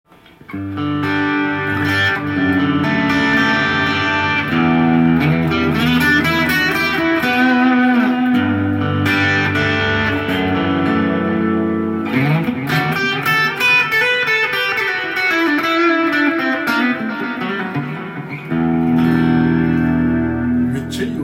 という空間系エフェクターです。
アンディーのような音が出るので高級感たっぷりでした。